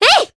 Ophelia-Vox_Attack1_jp.wav